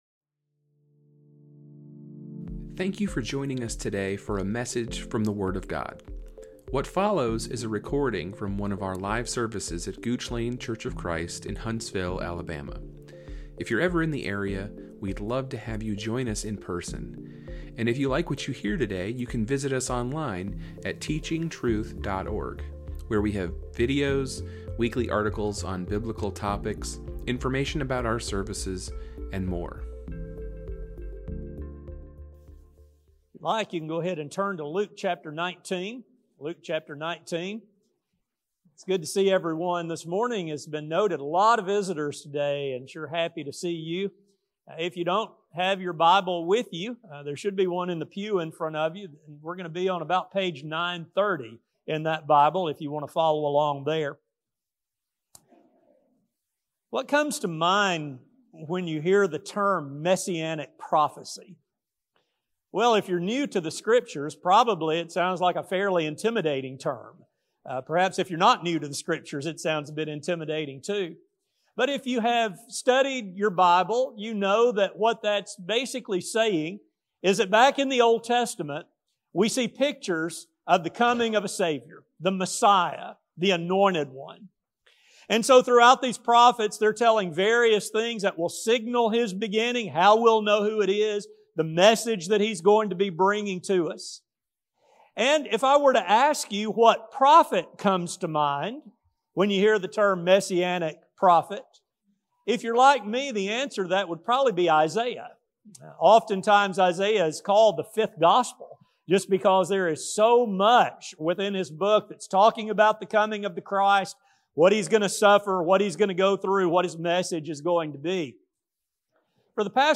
This sermon will explore some of these similarities while seeking to make points to a modern audience of what has been done for our salvation and how we must avoid the pitfalls into which the enemies of both Jeremiah and Jesus fell. A sermon